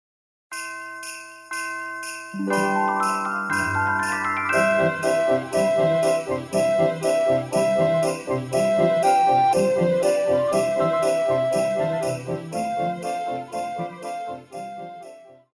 古典
高音直笛
樂團
聖誕歌曲,聖歌,教會音樂,古典音樂
獨奏與伴奏
有主奏
有節拍器